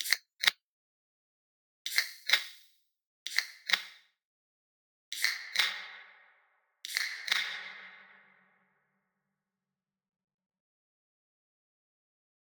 Estos cambios se deben a la reverberación, que es el efecto que genera el sonido al rebotar y expandirse de distintas maneras según los ambientes.
Ejemplo de reverberación
ejemplo-de-reverberacion-relatos-sonoros.wav